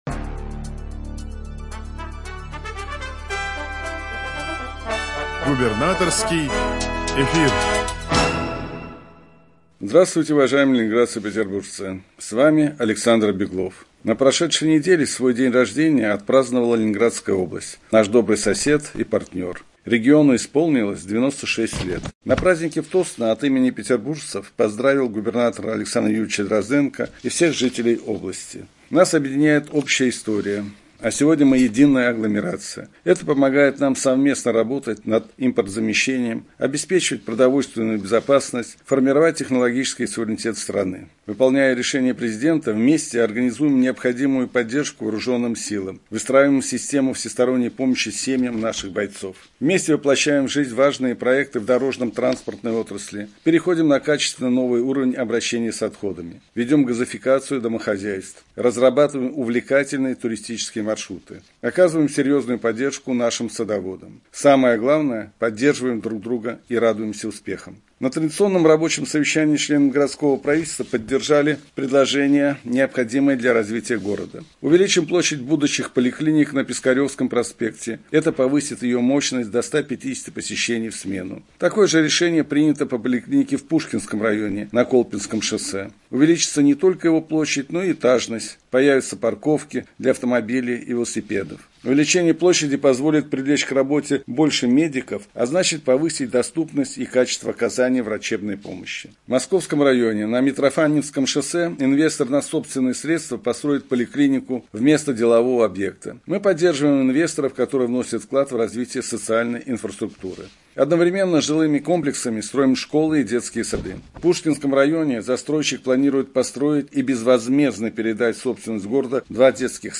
Радиообращение 7 августа 2023 года